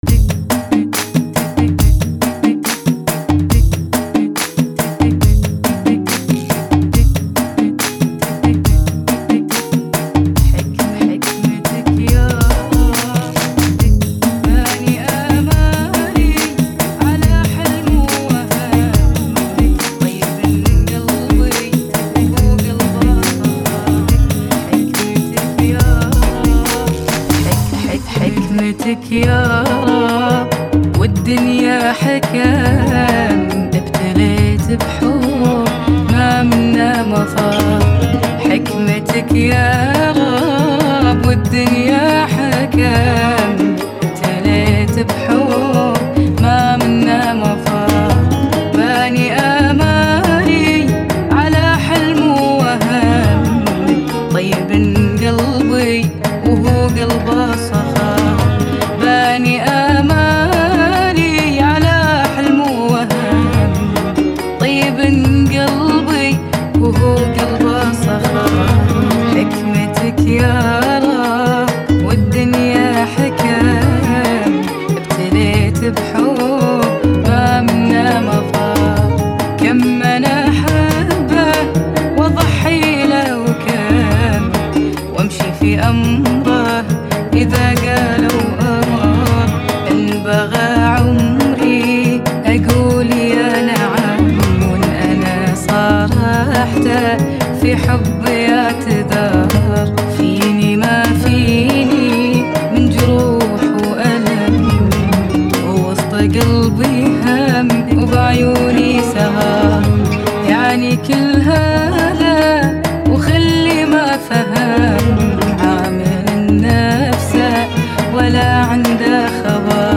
[ 70 bpm ] 2022